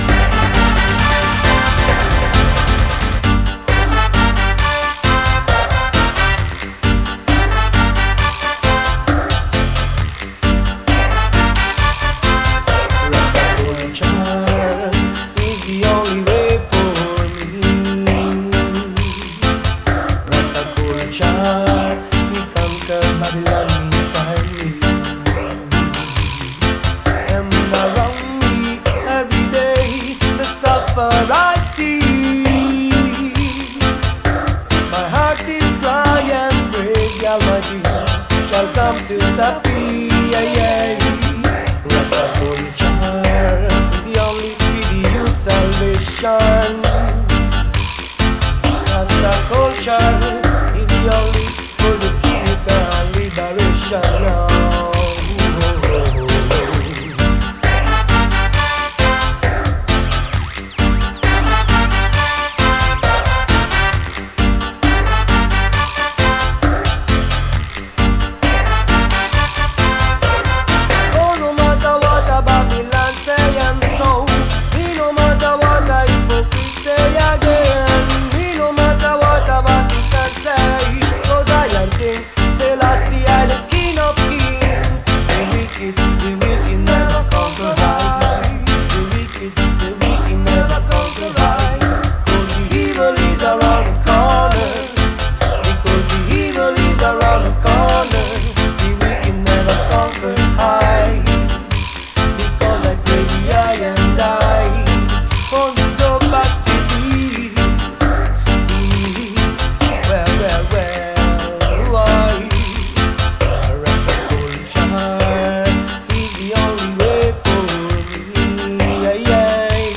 Rooms Full A Culture returned to the Rock & Roll Circus in Leeds, for a great session, with both rooms running on pure vibes all night!
bringing a great energy through Beet Roots Soundsystem.